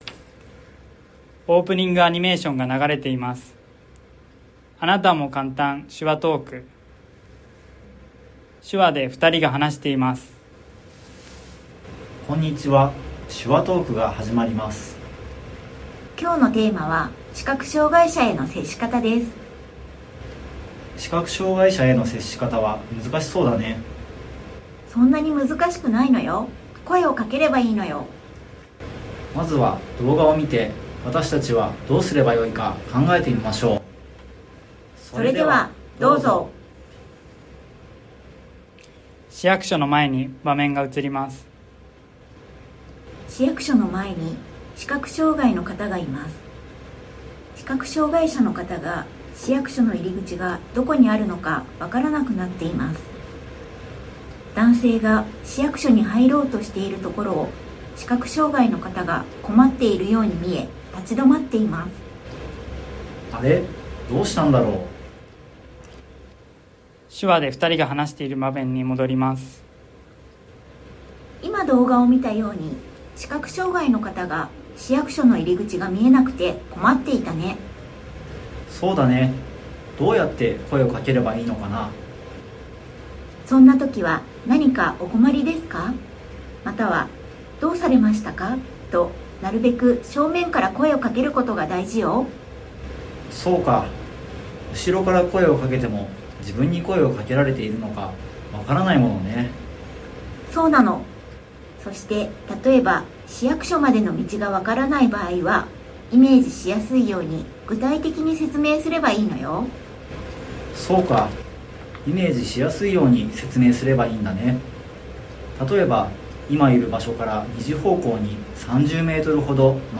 読み上げデータ（音声・音楽：3,809KB）
shikakuyomiage.mp3